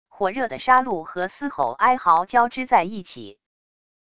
火热的杀戮和嘶吼哀嚎交织在一起wav音频